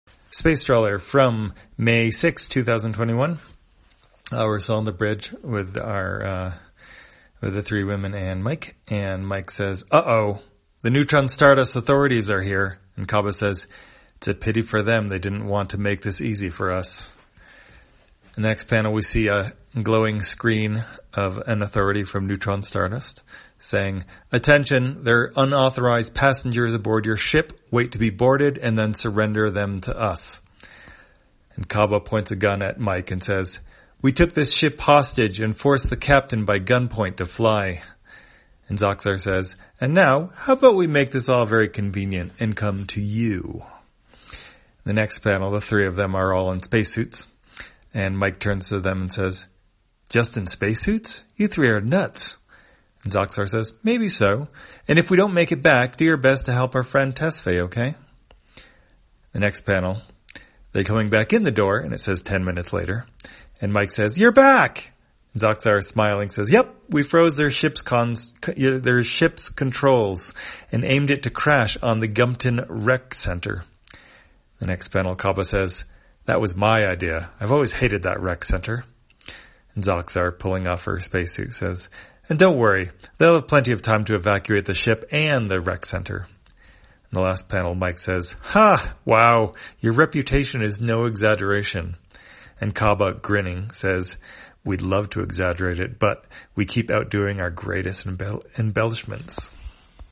Spacetrawler, audio version For the blind or visually impaired, May 6, 2021.